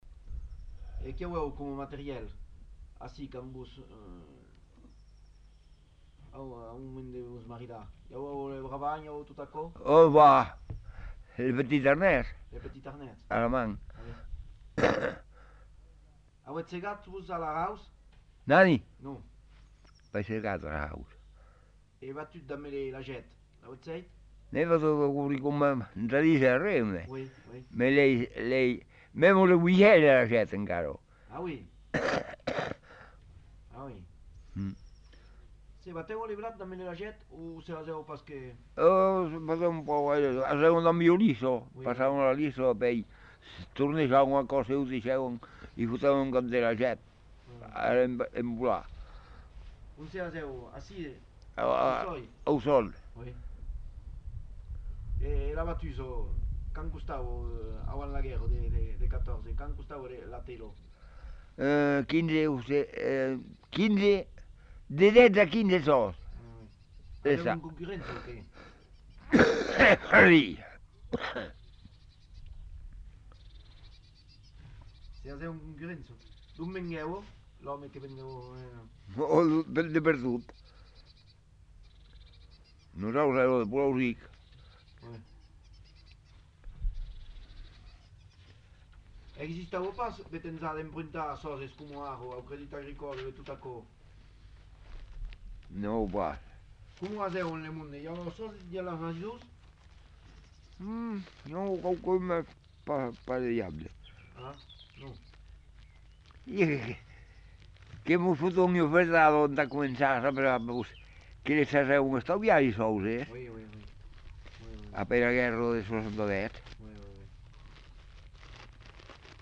Lieu : Montadet
Genre : témoignage thématique